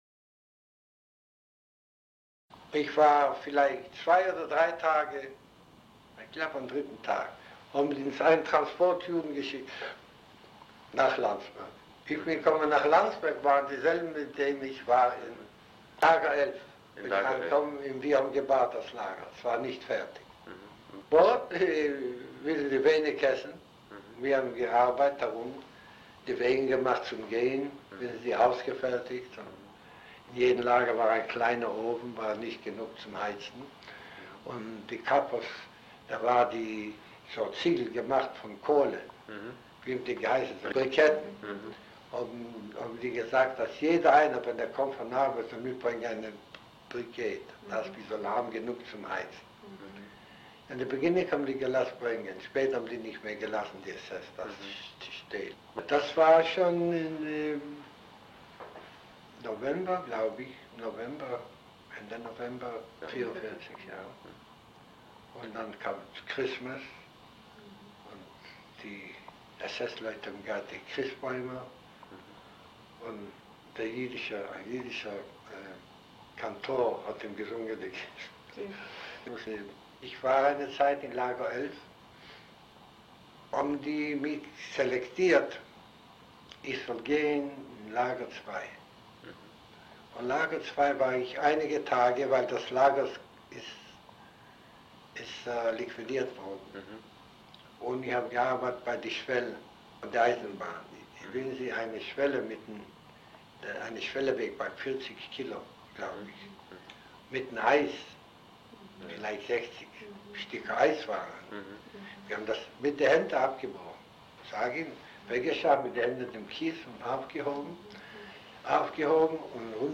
Tondokument
Auszug aus dem Interview